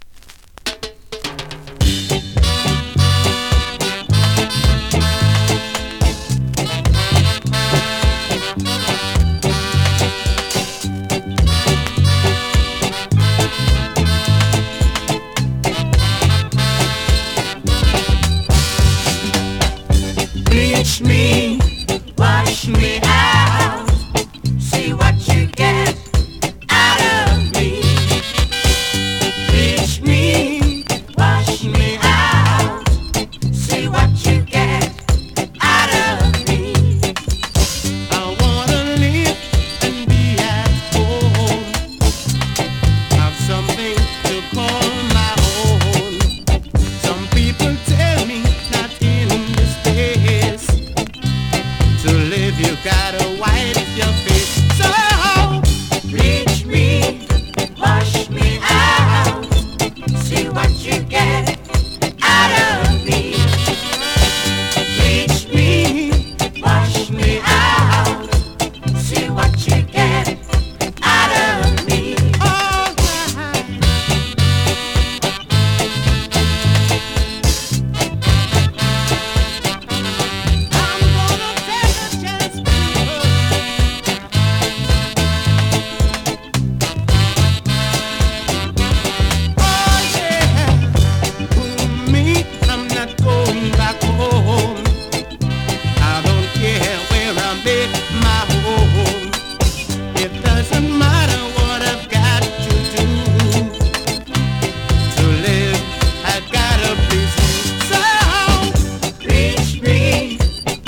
2025!! NEW IN!SKA〜REGGAE
スリキズ、ノイズ比較的少なめで